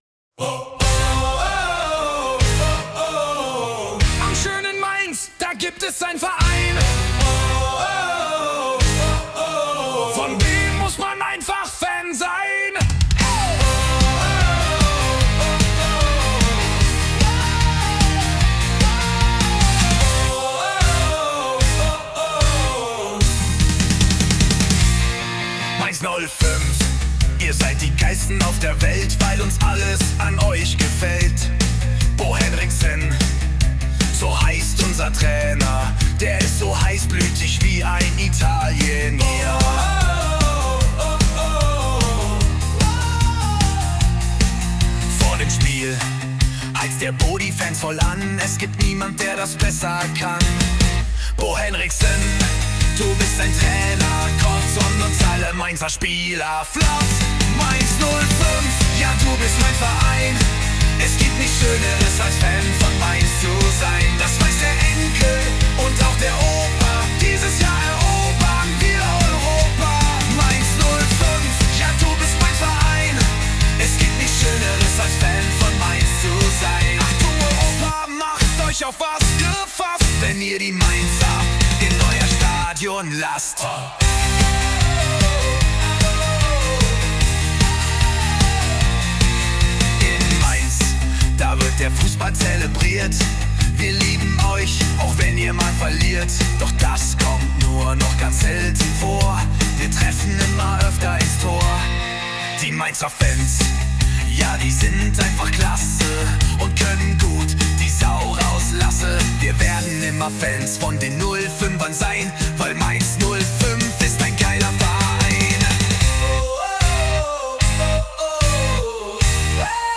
Mit Hilfe von KI erstellt.